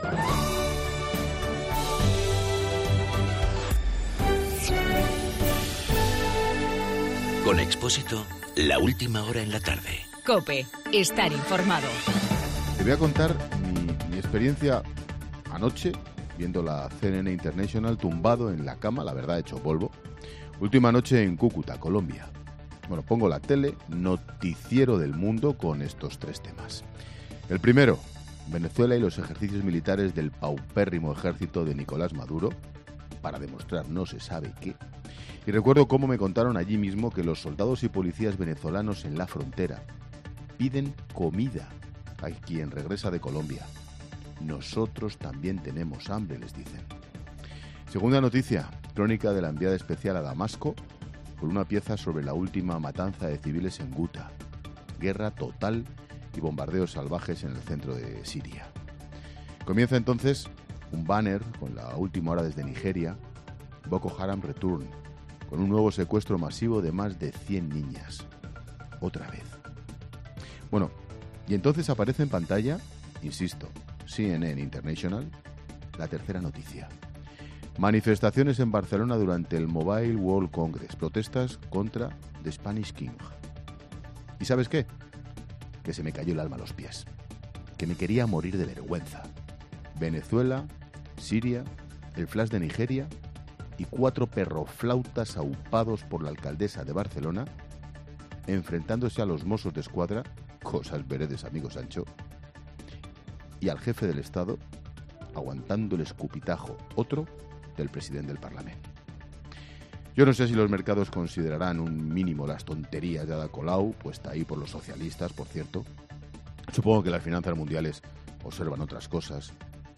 AUDIO: El comentario de Ángel Expósito de regreso a España desde Cúcuta (Colombia).